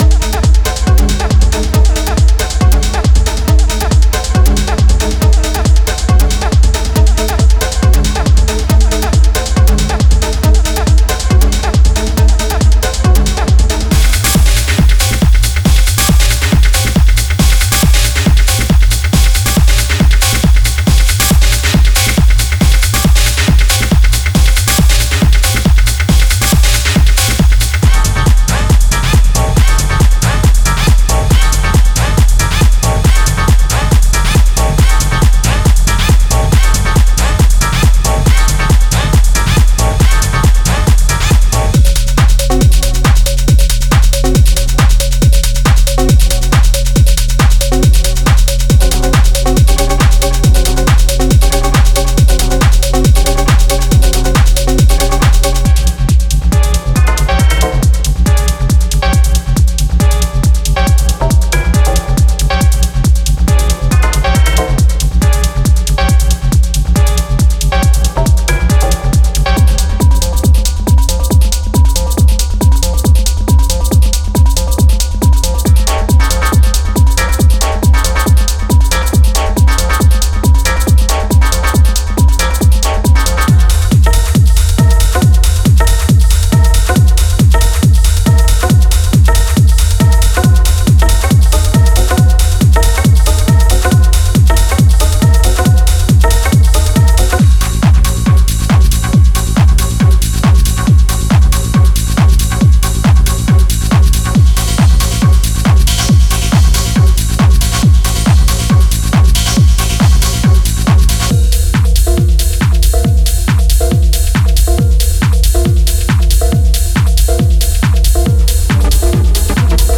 Genre:Techno
テクスチャーは深く、ドラムは鋭く、シンセは動く機械のように流れます。
デモサウンドはコチラ↓